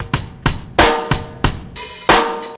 Old R_B 93.4bpm.wav